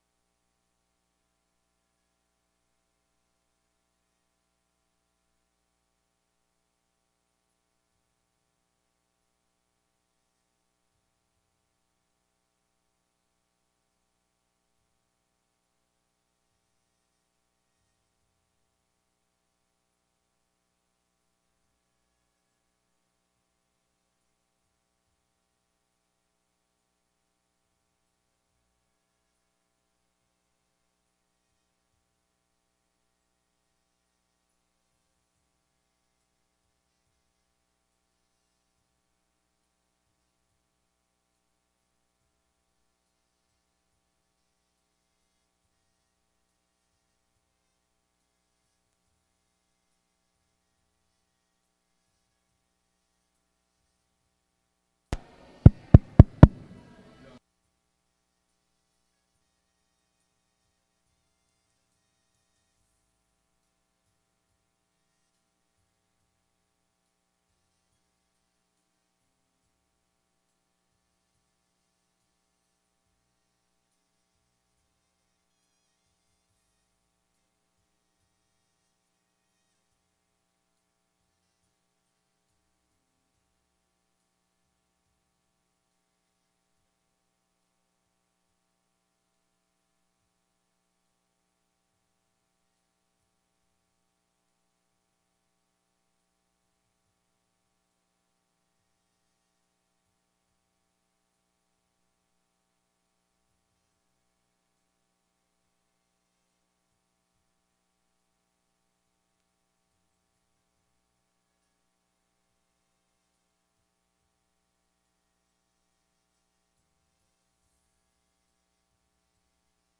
Sessões Solenes